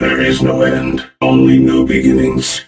robo_bo_die_03.ogg